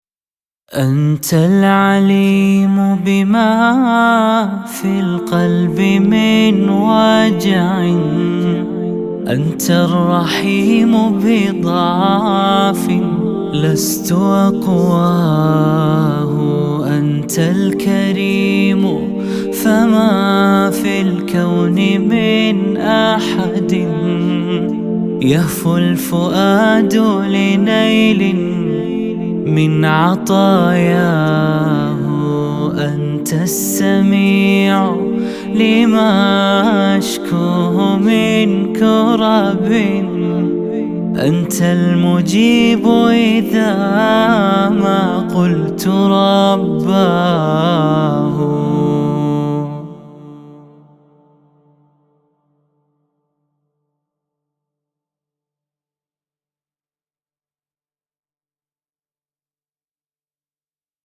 نشيد